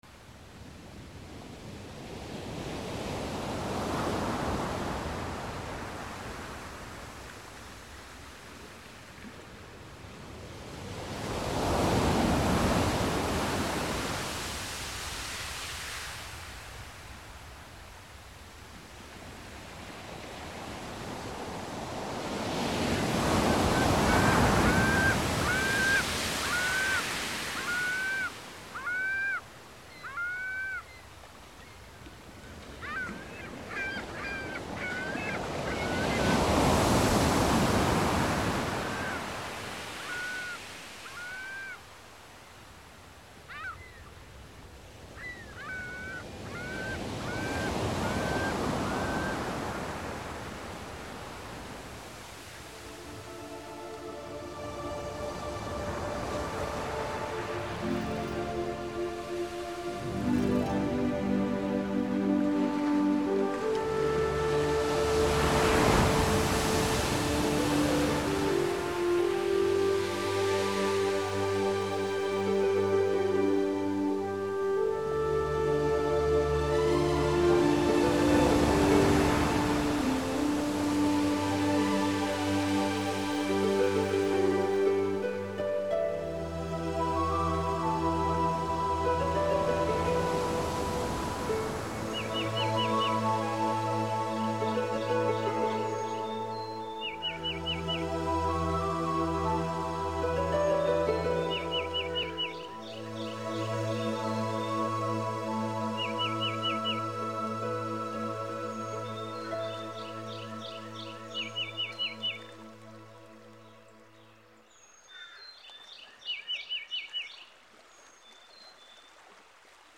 他建议将精心制作的乐曲与大自然的天籁结合，相得益彰之下，更能营造一种动人的听觉环境。